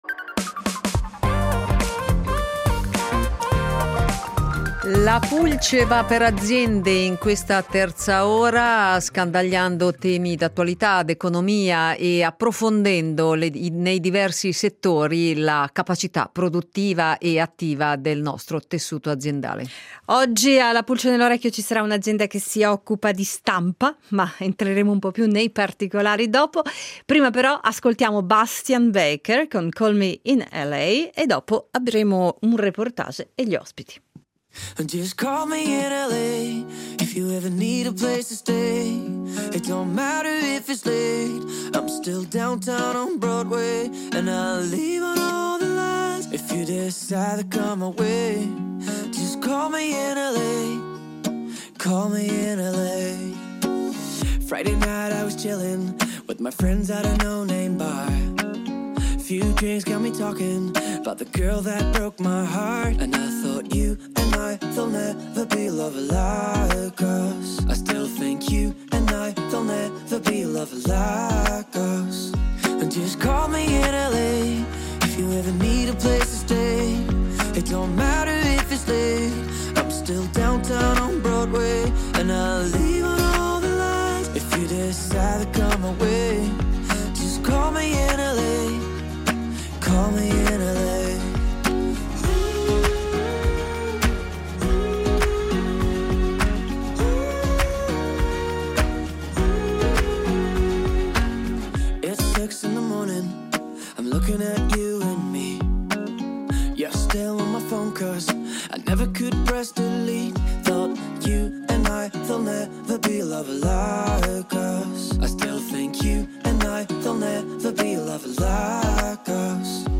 E' attiva nelle principali aree del settore: stampati commerciali, editoria con la produzione di libri e riviste, packaging e stampa in rotativa di formulari per computer e giornali. Dopo il reportage realizzato tra innovative macchine tipografiche, in studio